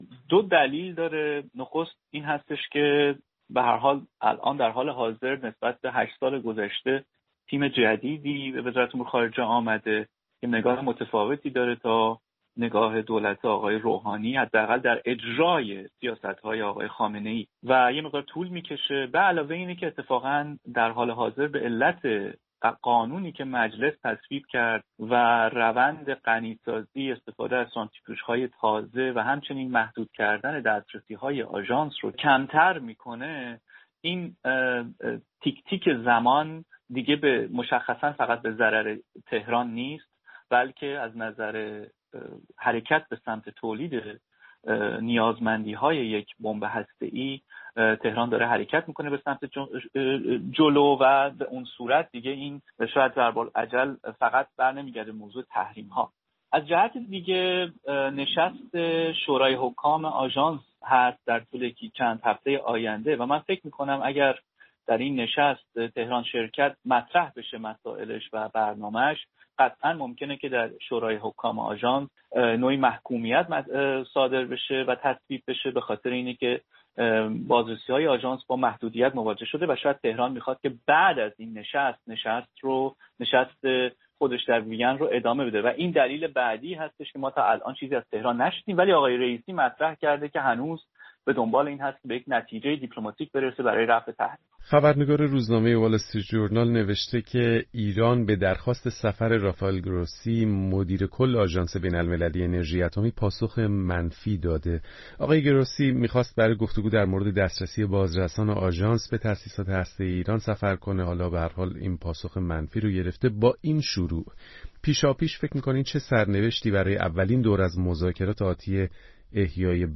گفت‌وگویی